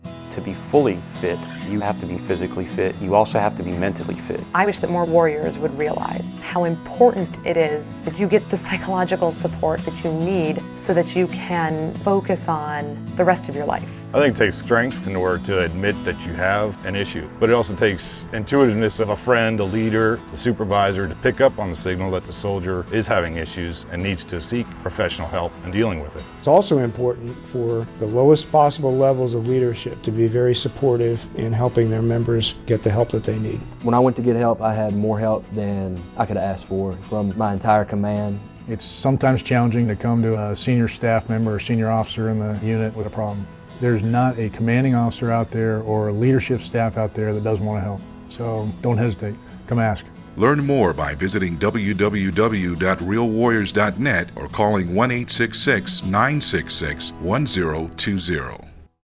February 24, 2012Posted in: Public Service Announcement